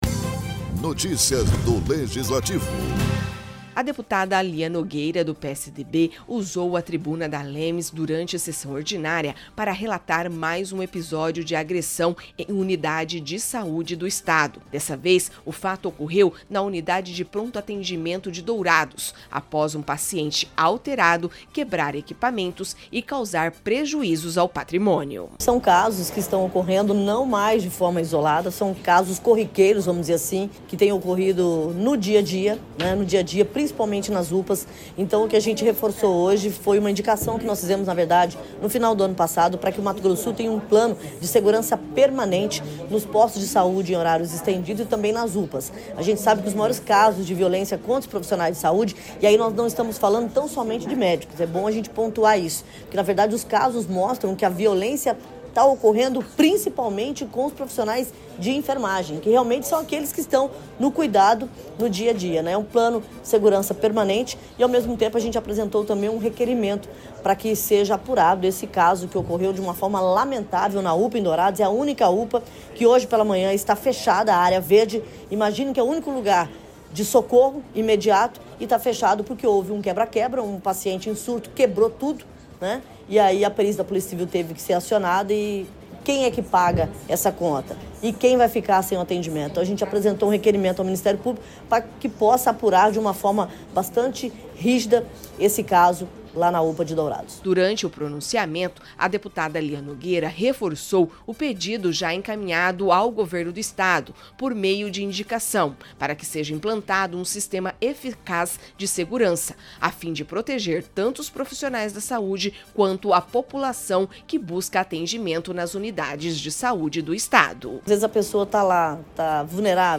Na tribuna da ALEMS, a deputada Lia Nogueira (PSDB) relatou mais um episódio de agressão em unidade de saúde, após um paciente alterado quebrar equipamentos e causar prejuízos na UPA de Dourados.